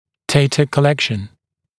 [‘deɪtə kə’lekʃn][‘дэйтэ кэ’лэкшн]сбор данных, сбор информации